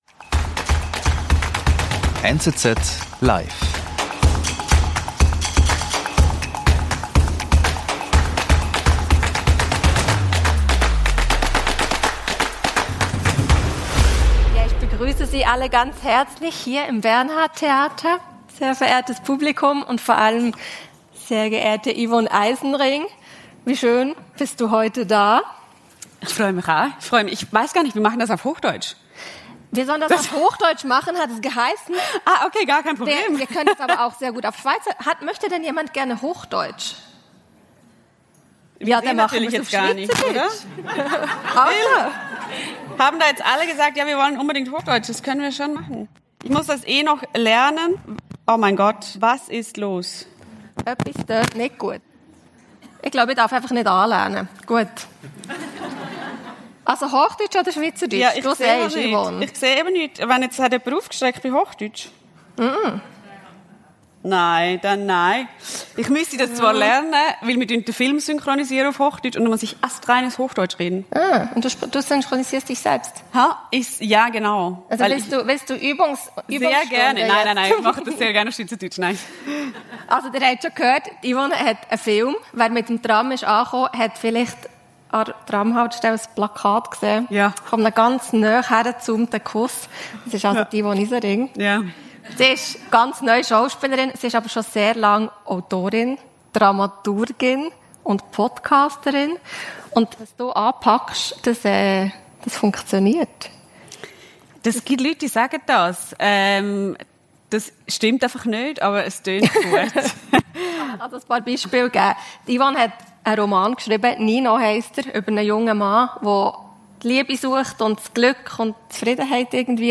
Ein Gespräch über Familie und Freiheit, finanzielle Unsicherheiten und grosse Träume, das Grelle im Rampenlicht und die eigenen Grenzen.